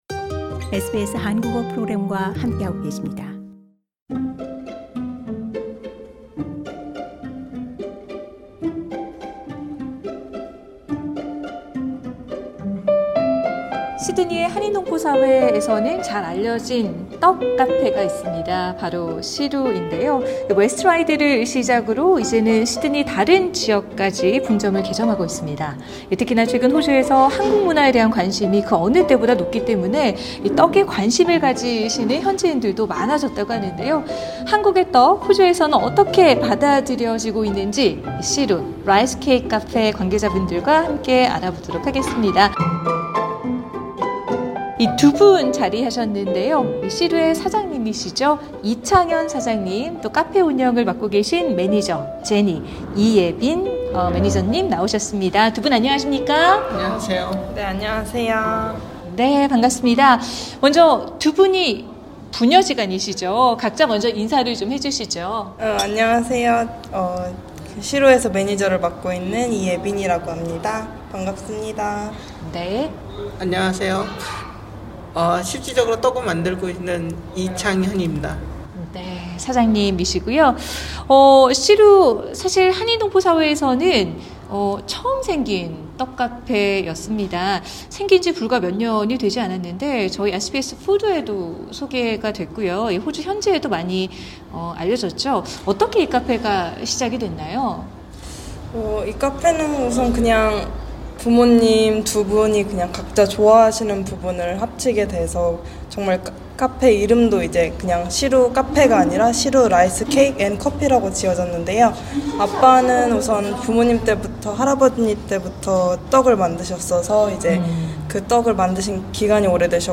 인터뷰: 시드니 떡 카페 ‘시루’, “글루텐 프리에 비건까지…